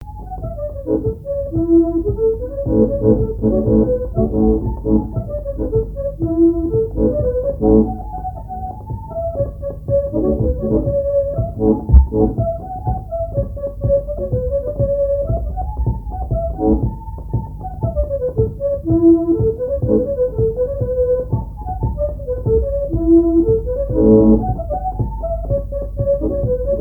danse : angoise, maristingo
Répertoire à l'accordéon diatonique
Pièce musicale inédite